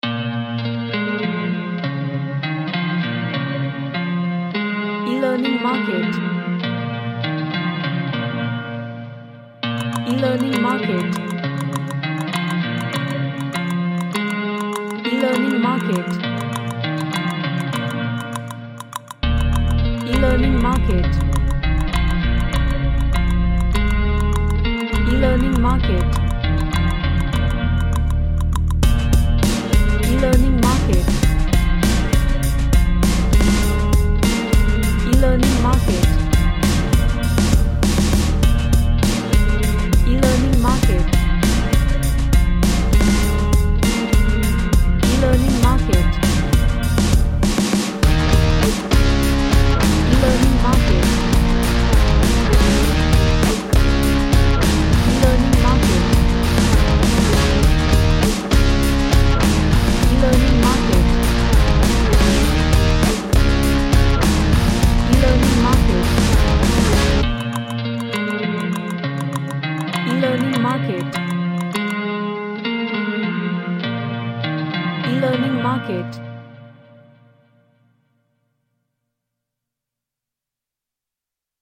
A rock track with 90s vibe and instrumentations
Strange / Bizarre